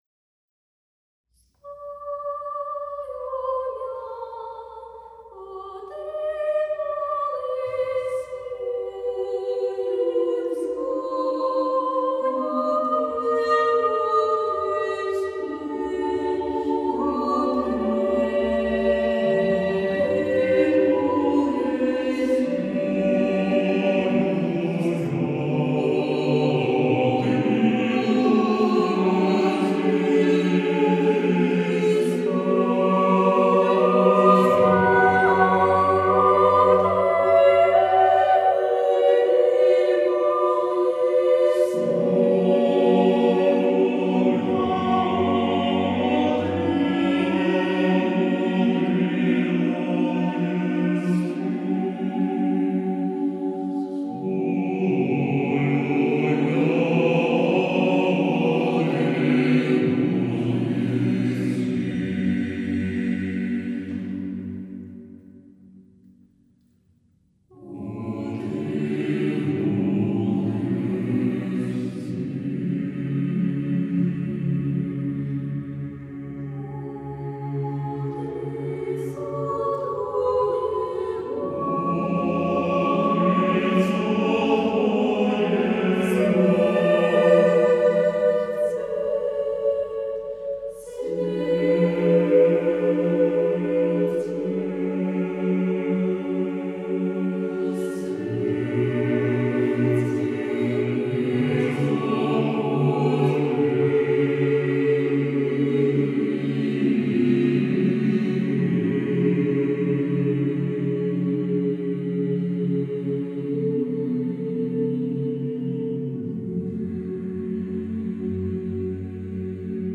Галерея Хор Собора Св. Ал.Невского